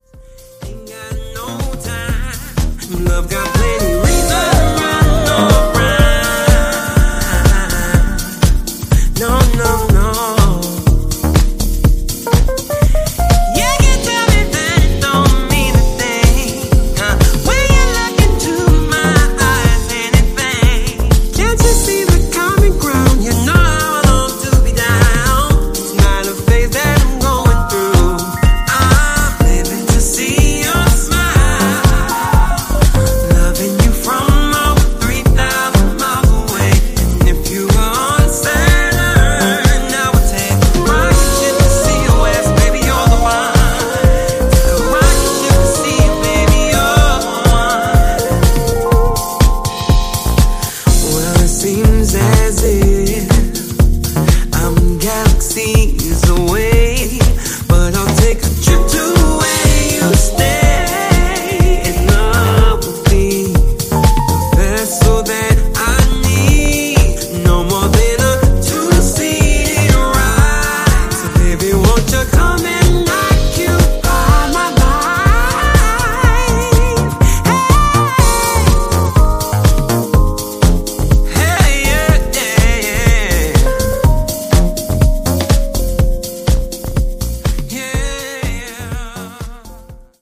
ヴォーカルが気持ち良すぎる素晴らしいナンバー！
ジャンル(スタイル) SOULFUL HOUSE / HOUSE